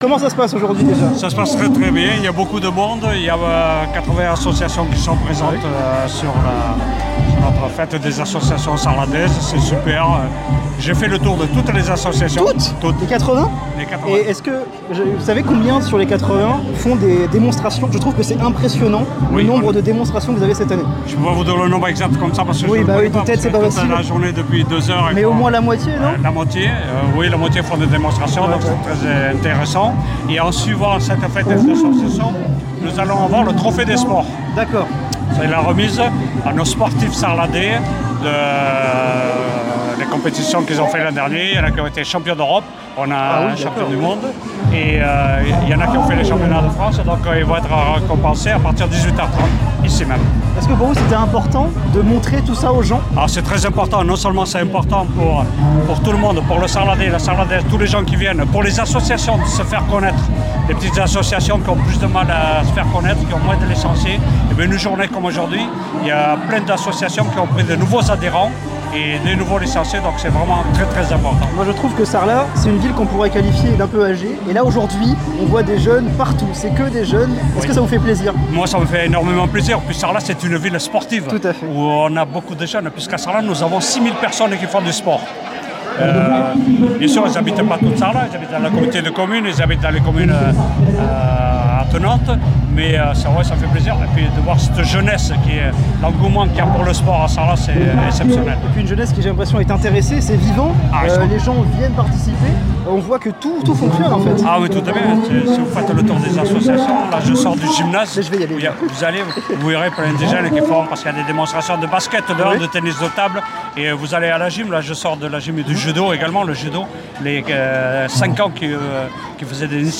Week-end des associations 2025